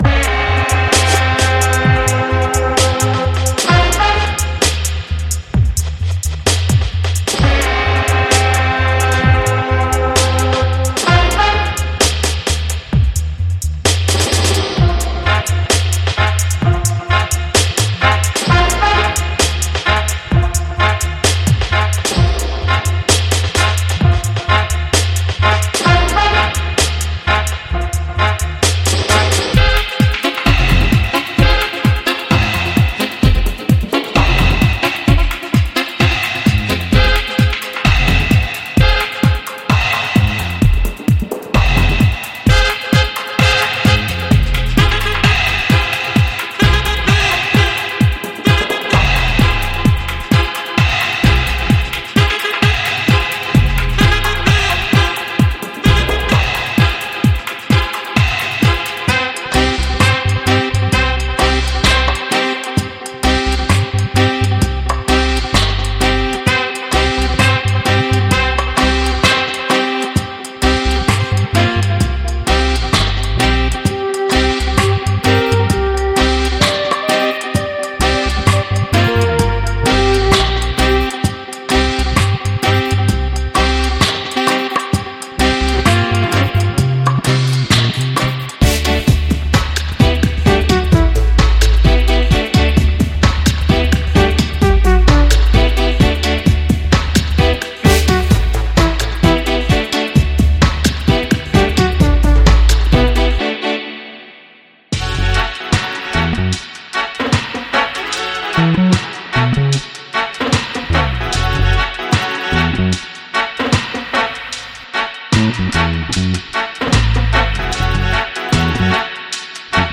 レゲエ、ダブ、ジャングルなどに欠かせないホーンスカンク＆ワンショット
ホーンスカンク – 伝統的なレゲエやダブのグルーヴに最適なタイトでリズミカルなブラススタブ
セレクトリフ – カラーやリードラインを加えるためのメロディックフレーズ
フルートライン – 微妙なレイヤーに重ねる雰囲気のあるソウルフルなフレーズ
クラシックダブ＆レゲエ
収録楽器はトランペット、トロンボーン、サックス、フルートで、ソロラインとコンボラインの両方を収録しています。
デモサウンドはコチラ↓
Genre:Dub